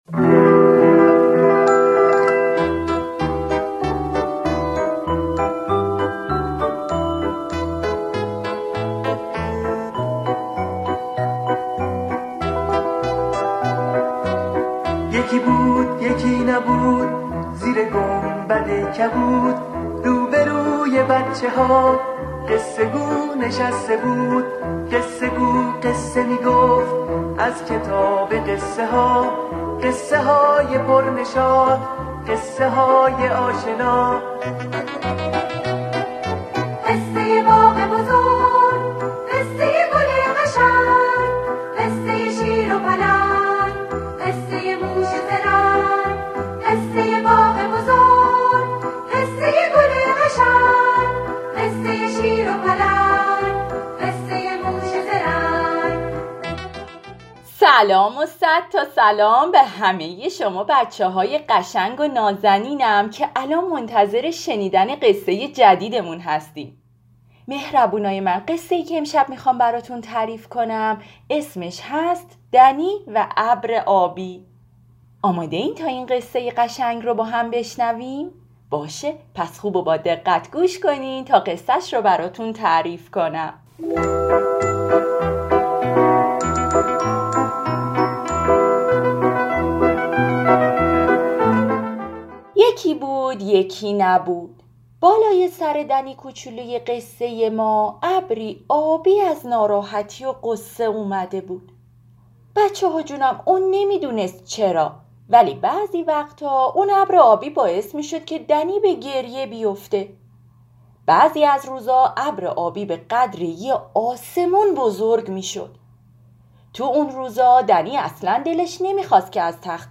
کتاب صوتی دنی و ابر آبی